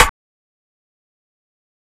Metro Mean Perc.wav